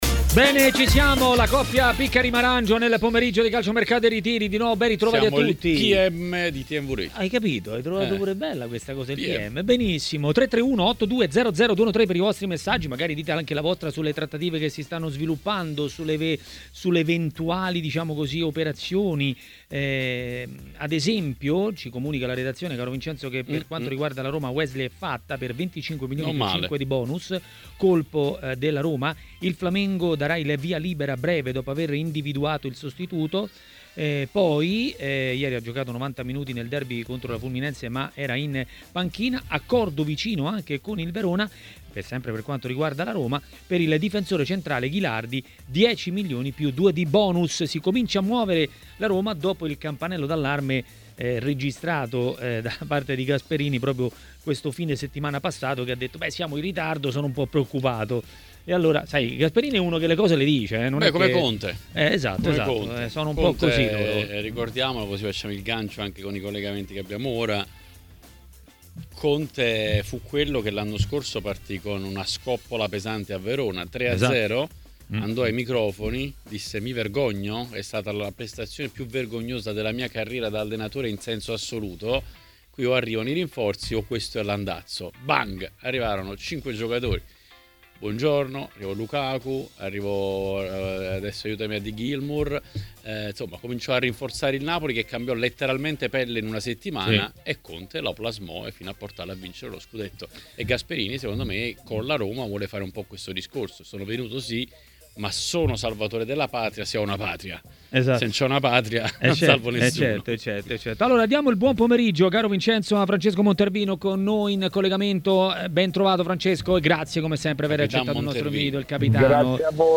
Per parlare di Napoli a TMW Radio, durante Calciomercato e Ritiri, è intervenuto l'ex calciatore Francesco Montervino.